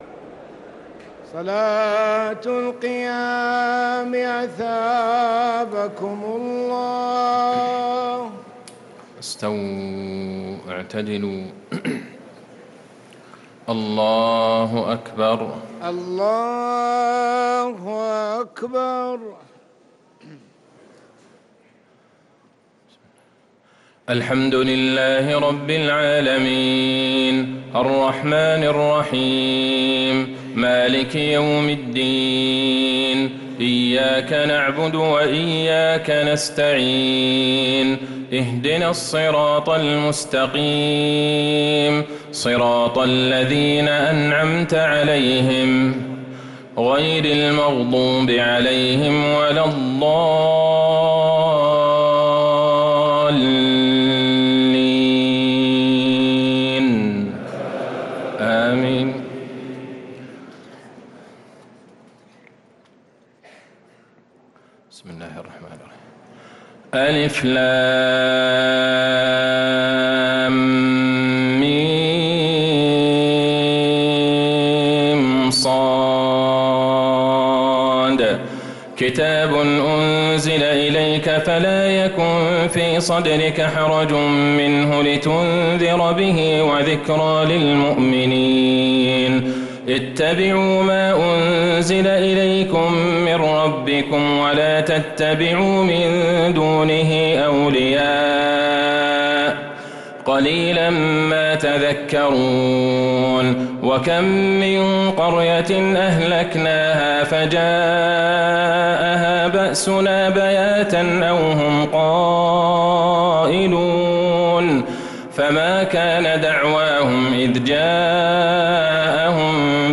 تراويح ليلة 11 رمضان 1446هـ من سورة الأعراف (1-79) | Taraweeh 11th niqht Ramadan Surat Al-A’raf 1446H > تراويح الحرم النبوي عام 1446 🕌 > التراويح - تلاوات الحرمين